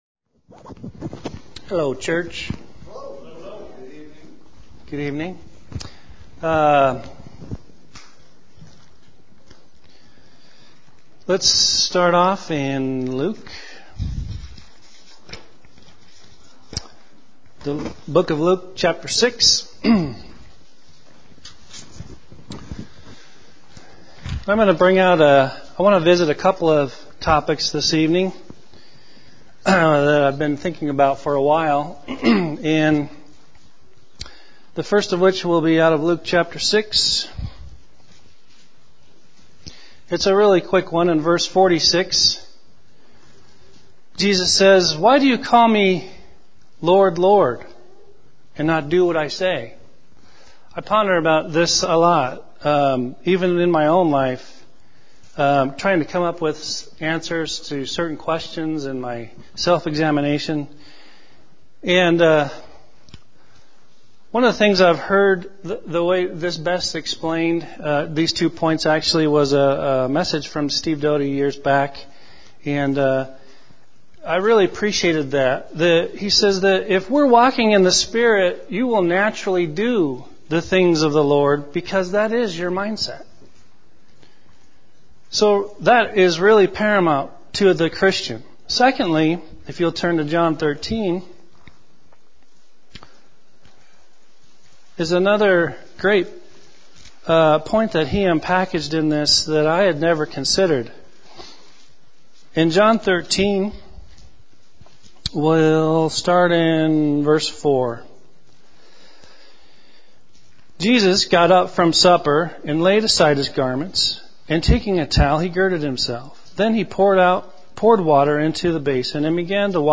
Series: Evening Messages